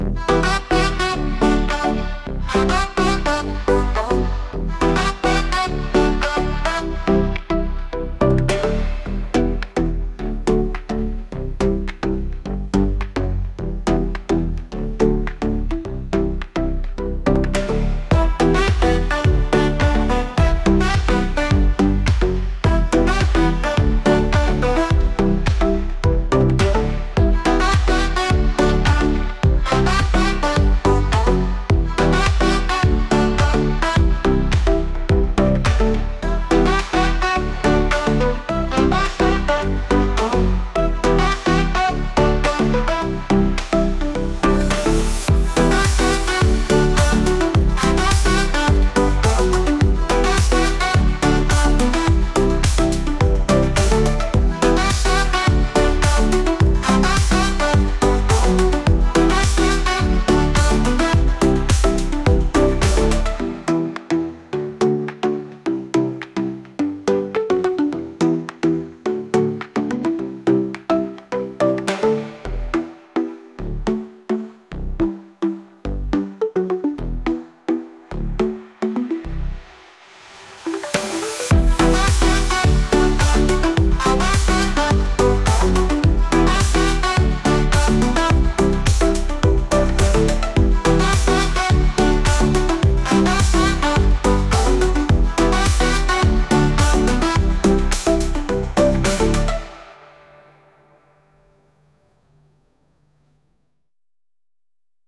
韓国風のエレクトリック曲です。